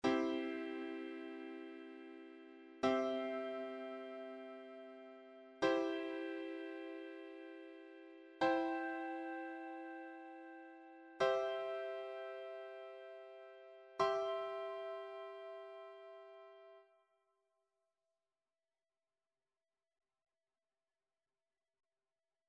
Cifrado e inversiones del acorde de Do mayor.
Audio de elaboración propia. Estados del acorde. (CC BY-NC-SA)